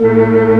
Index of /90_sSampleCDs/Giga Samples Collection/Organ/Barton Melo 16+8